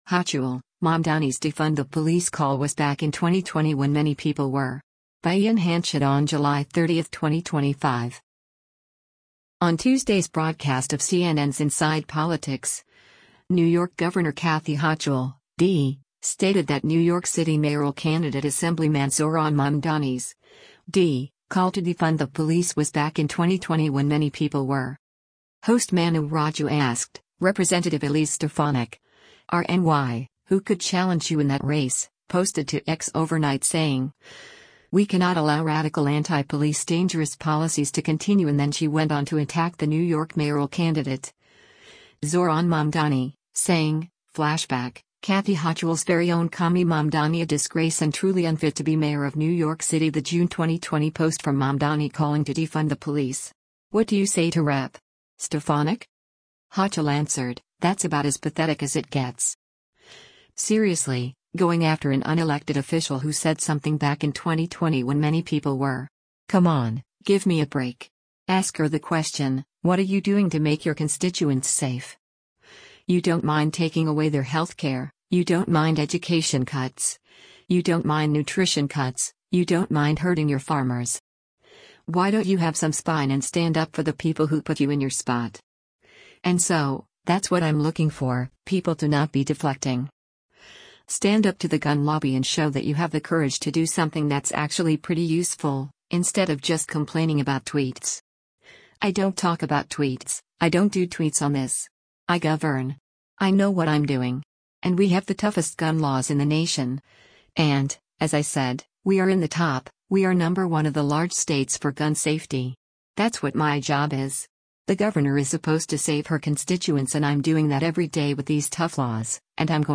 On Tuesday’s broadcast of CNN’s “Inside Politics,” New York Gov. Kathy Hochul (D) stated that New York City mayoral candidate Assemblyman Zohran Mamdani’s (D) call to defund the police was “back in 2020 when many people were.”